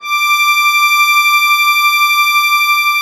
MUSETTESW.18.wav